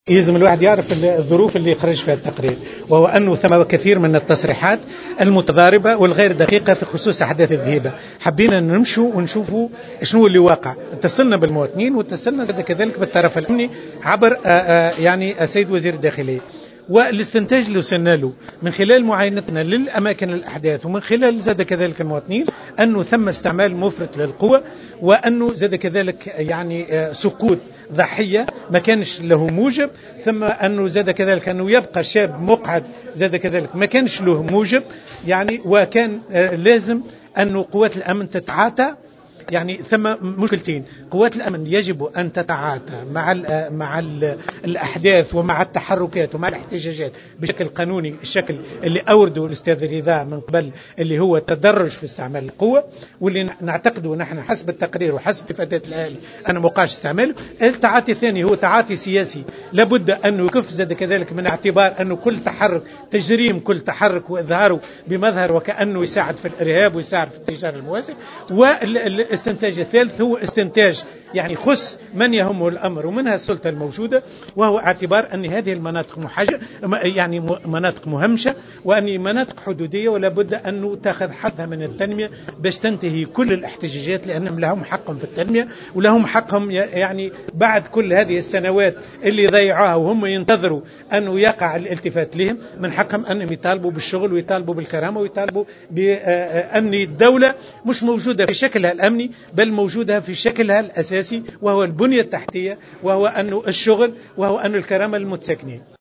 ندوة صحفية